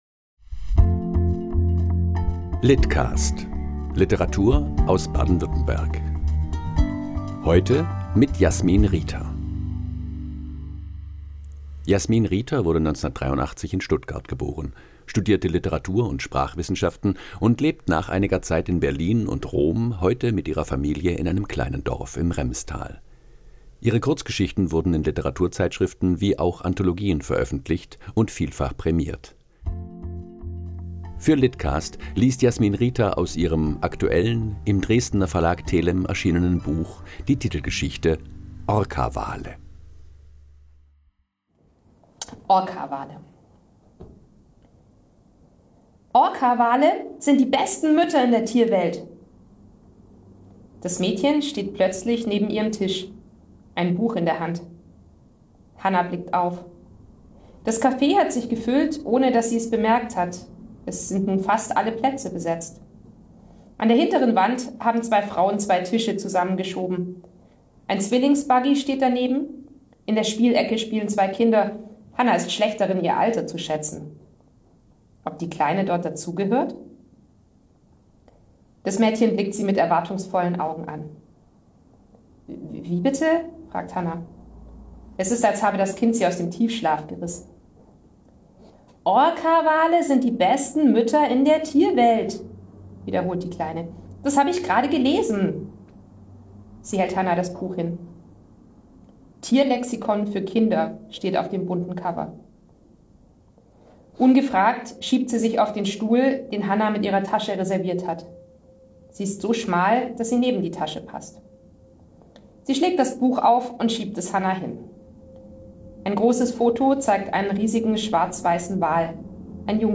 liest "Orcawale"